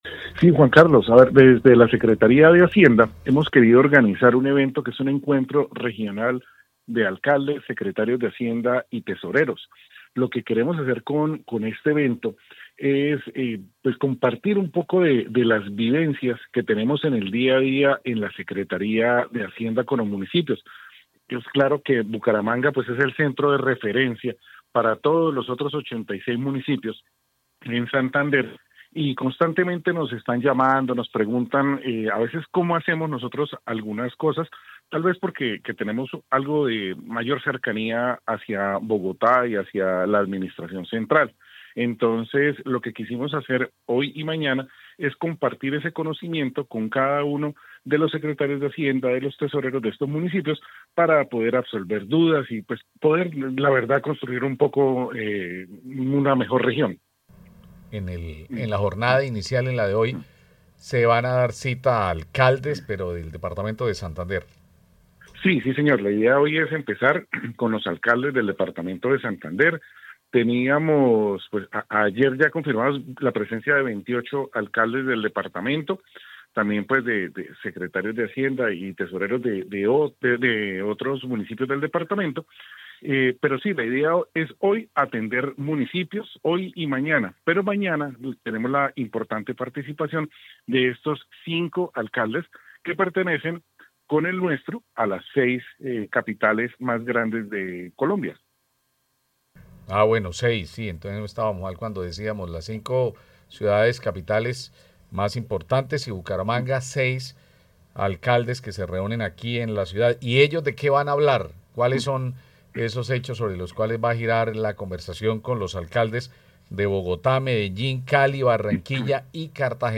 Reynaldo D’ Silva, secretario de hacienda de Bucaramanga